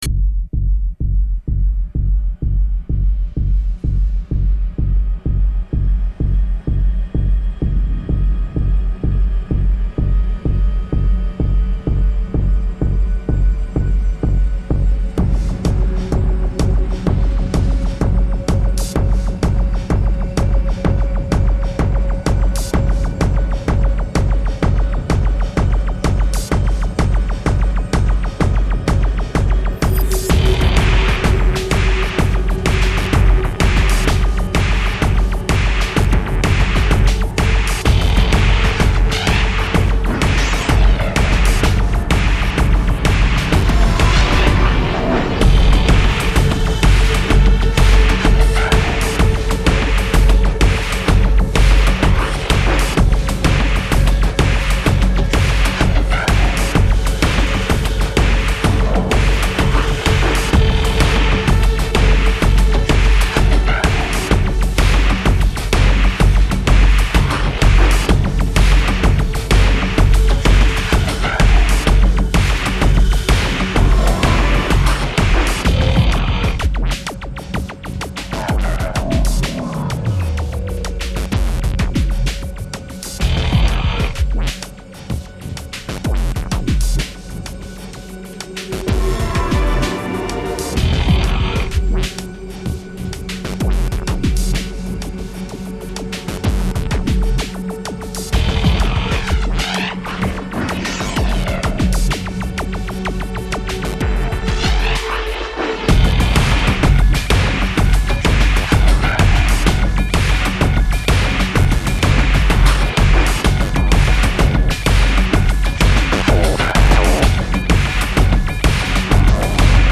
Транс.. Довольно жесткий.